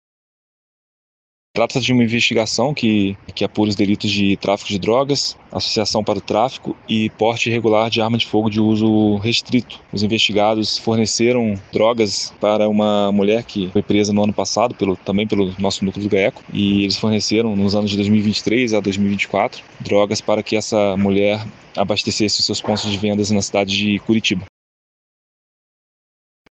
Quem detalha é o promotor de Justiça Fernando Carvalho Sant’ana.
SONORA-GAECO-OPERACAO-TRAFICO-CS.mp3